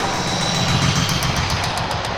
Index of /musicradar/rhythmic-inspiration-samples/110bpm
RI_DelayStack_110-02.wav